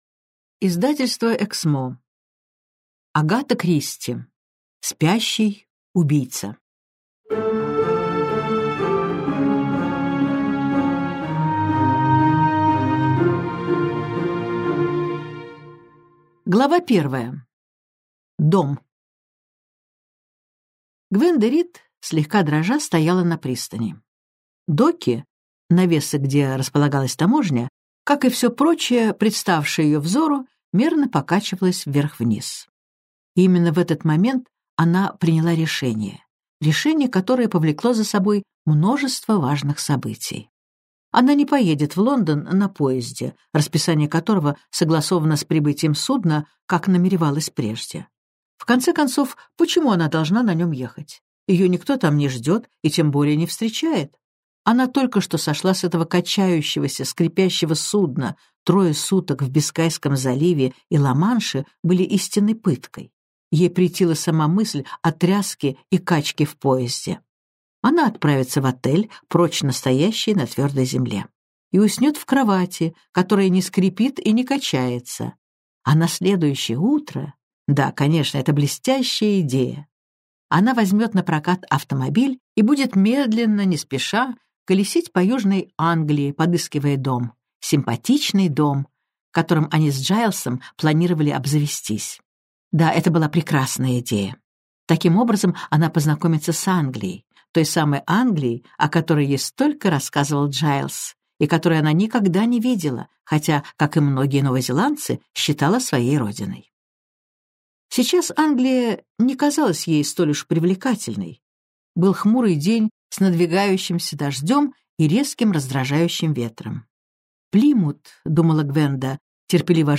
Аудиокнига Спящий убийца - купить, скачать и слушать онлайн | КнигоПоиск